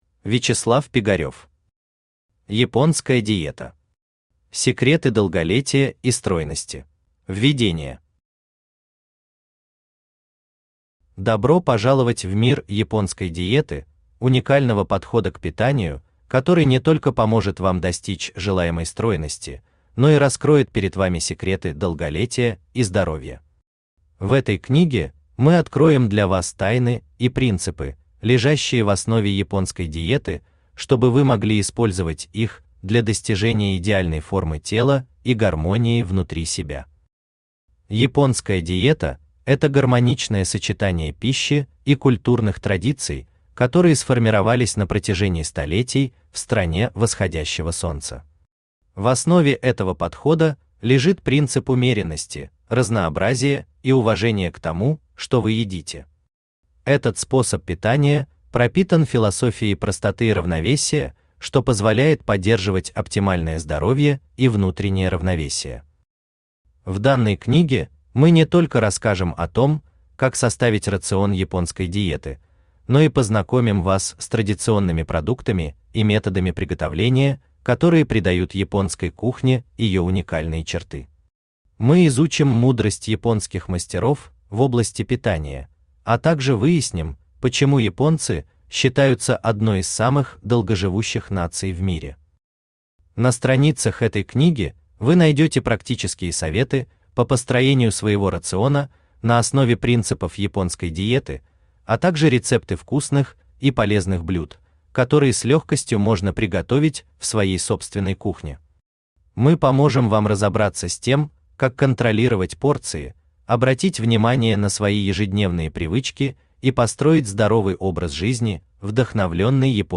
Аудиокнига Японская диета. Секреты долголетия и стройности | Библиотека аудиокниг
Секреты долголетия и стройности Автор Вячеслав Пигарев Читает аудиокнигу Авточтец ЛитРес.